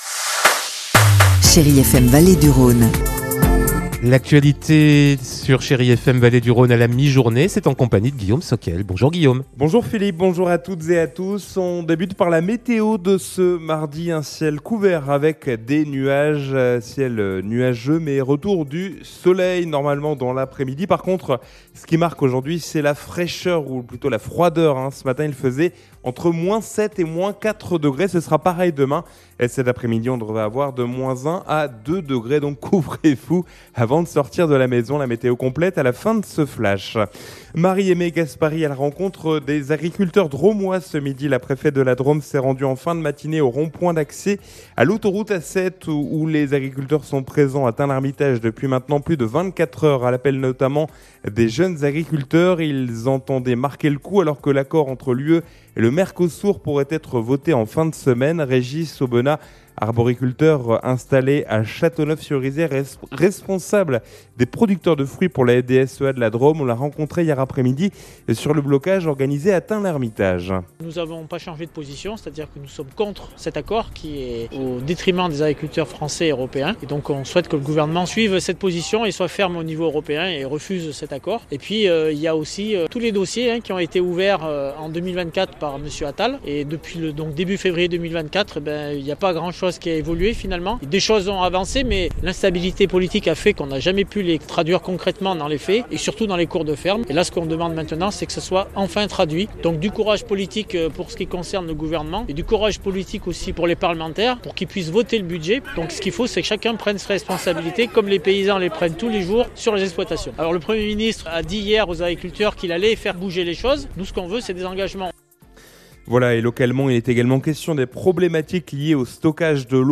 Mardi 6 janvier : Le journal de 12h